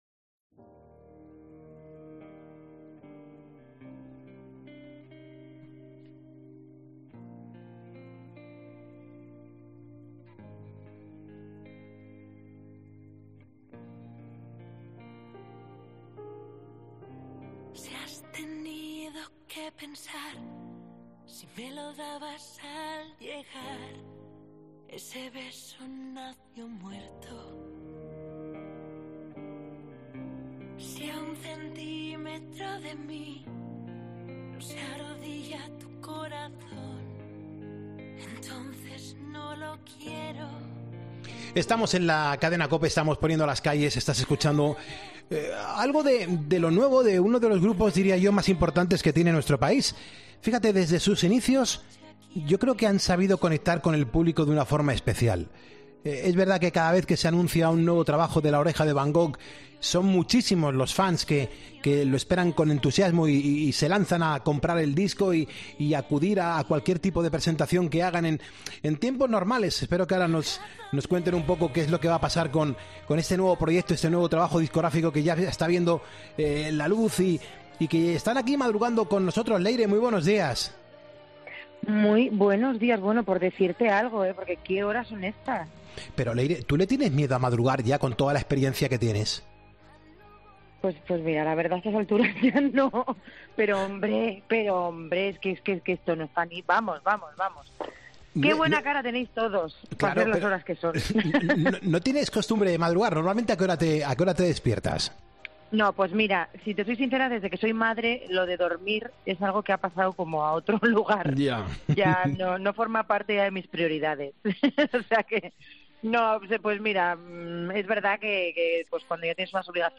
AUDIO: Leire, vocalista del grupo, nos atiende para contarnos todo sobre su último lanzamiento